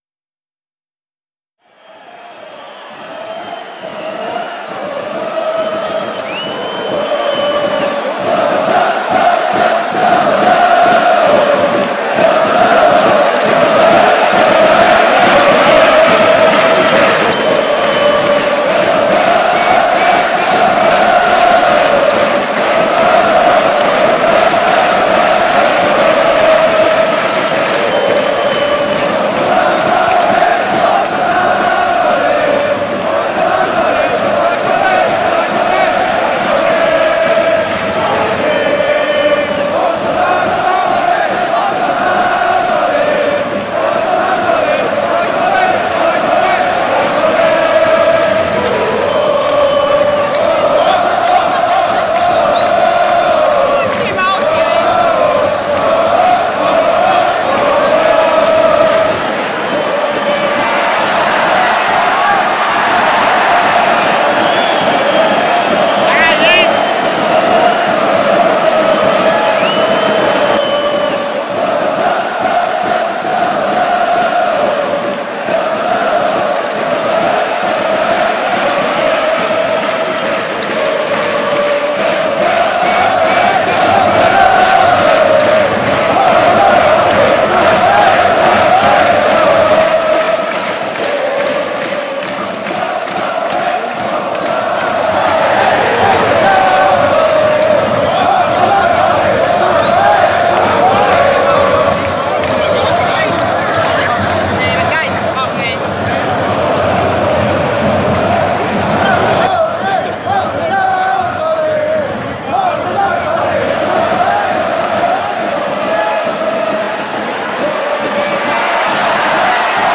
So I had three recordings of different games of FC St. Pauli, which all included "Forza St. Pauli".
I mixed the three recordings so that they should sound like a typical football game with an extended version of "Forza St. Pauli".
I was not in a cheerful mood this time, and created some dark sounds from the recordings. I mixed this with the happy song I started before and the result didn't make any sense.